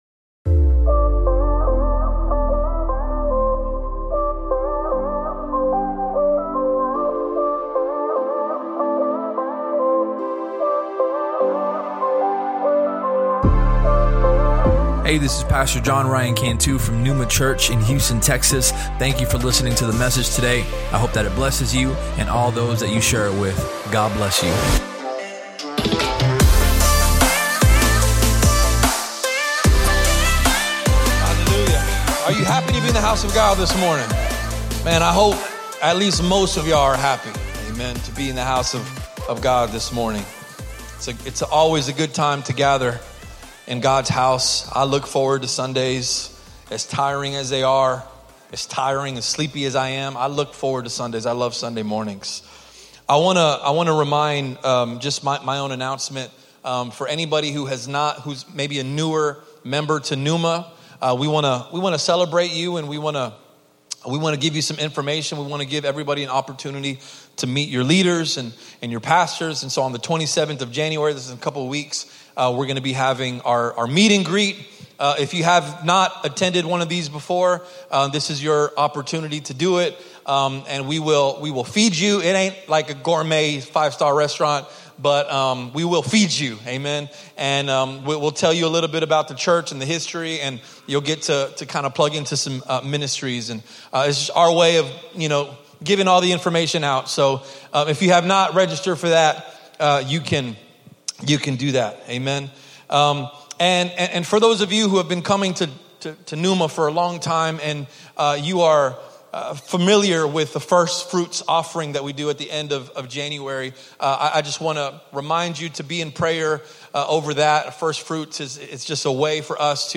Sermon Topics: Obedience, Sacrifice